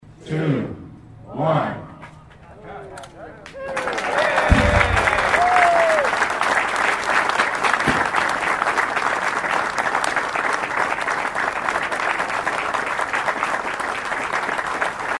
Officials cut the ribbon at the reopening of the U.S. Cavalry Museum at Fort Riley Tuesday morning.
0613-1-Ribbon-Cutting-Audio.mp3